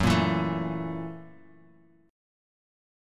Gb7#9 chord